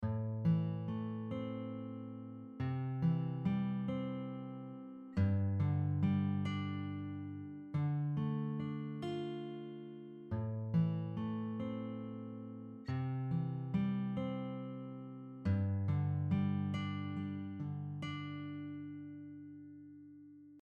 Slowed down audio example
It will be much easier to pick up the missing notes in each chord.
6-tips-to-play-songs-by-ear-audio-slowed-down.mp3